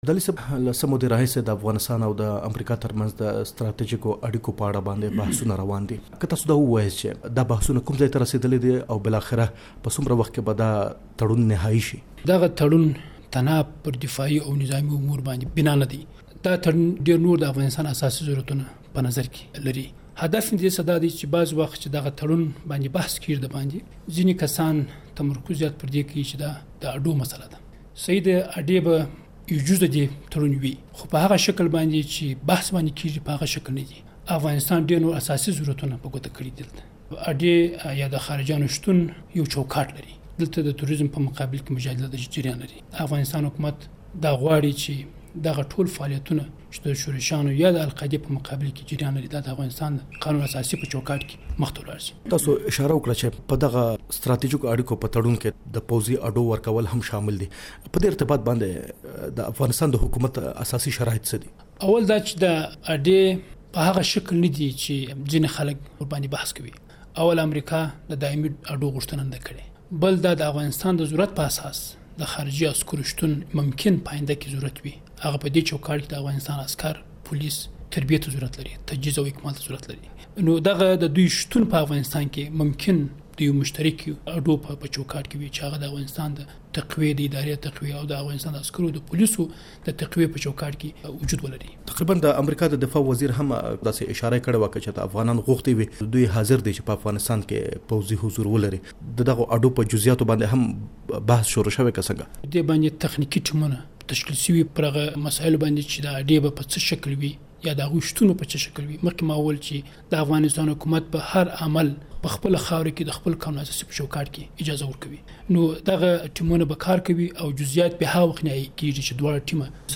له شیدا محمد ابدالي سره مرکه